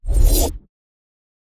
cast-generic-05.ogg